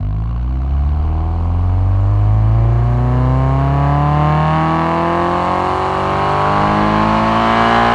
rr3-assets/files/.depot/audio/Vehicles/i4_03/i4_03_accel.wav
i4_03_accel.wav